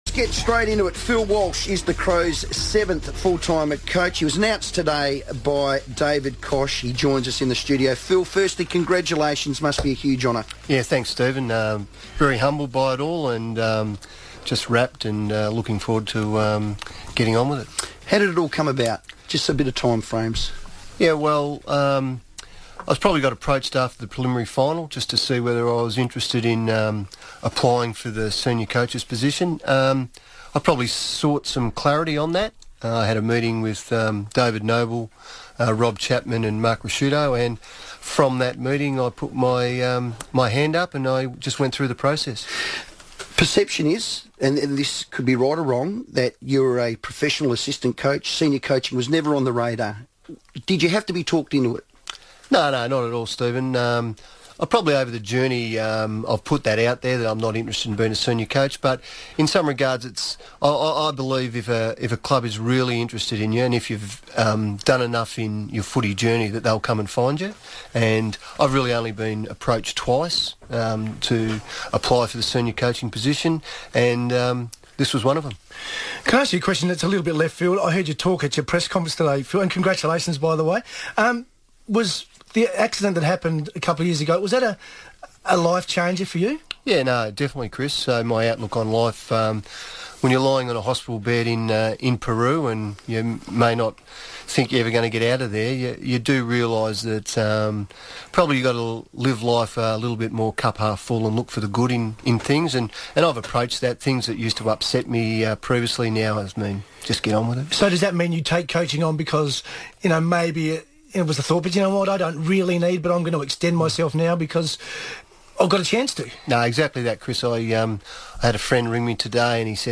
Phil Walsh joined the FIVEaa Sports Show after penning a three-year deal to lead Adelaide as the Club's new Senior Coach